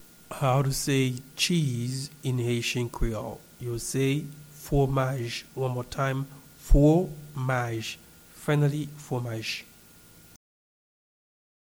Pronunciation and Transcript:
Cheese-in-Haitian-Creole-Fwomaj-pronunciation.mp3